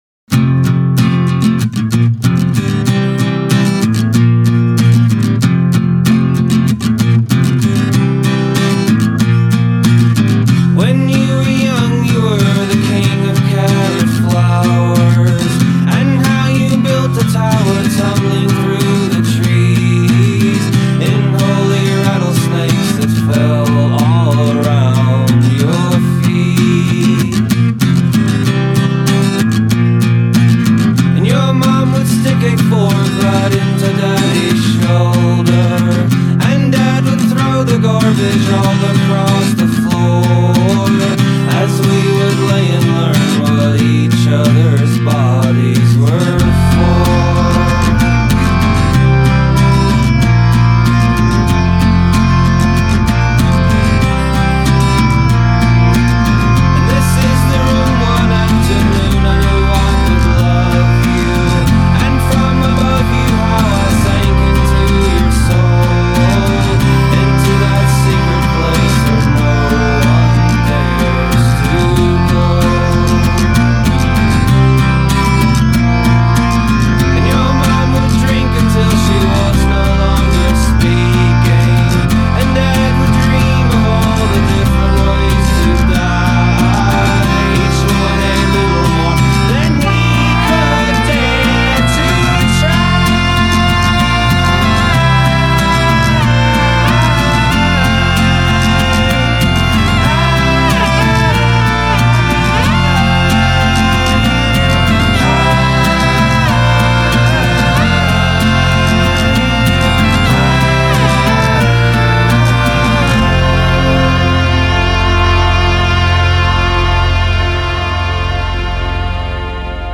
Tags1990s 1998 Folk Southern US